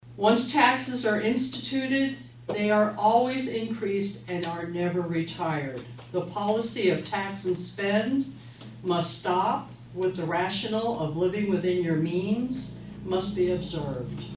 (Atlantic) The Atlantic City Council held a public hearing for the proposed Maximum Property Tax Levy for Fiscal Year 2025 Wednesday evening.